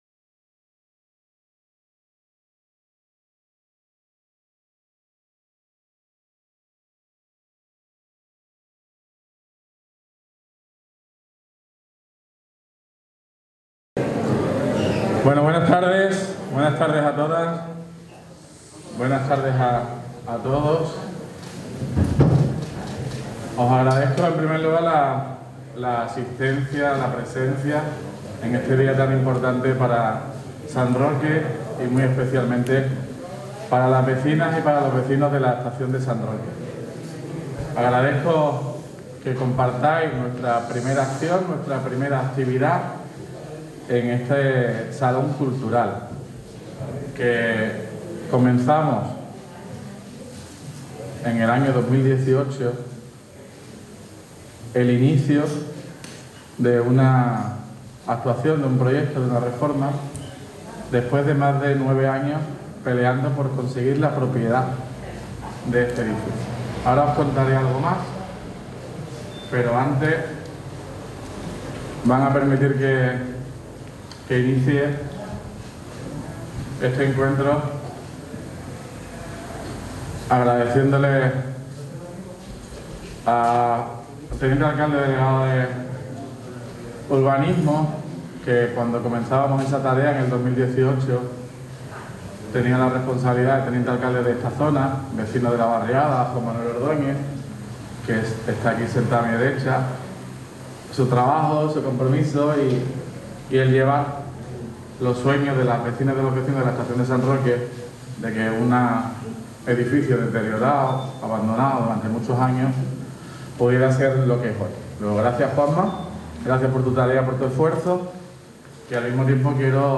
INAUGURACIÓN EDIFICIO RENFE.mp3